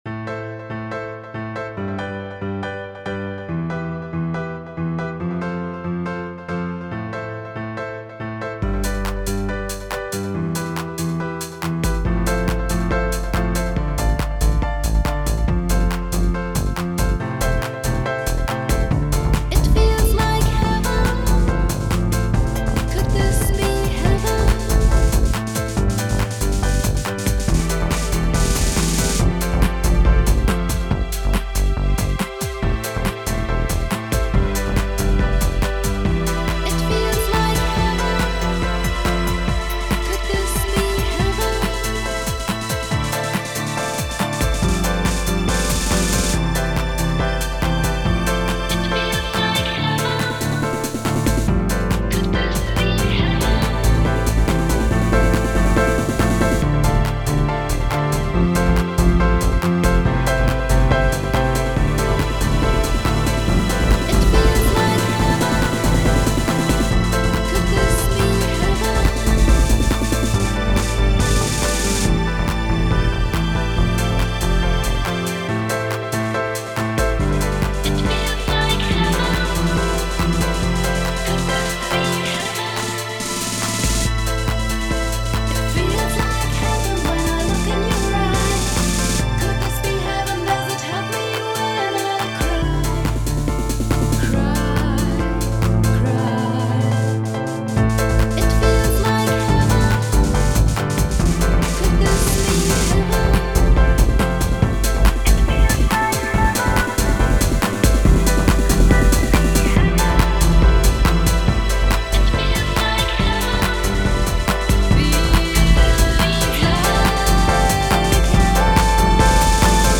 Home > Music > Electronic > Bright > Dreamy > Running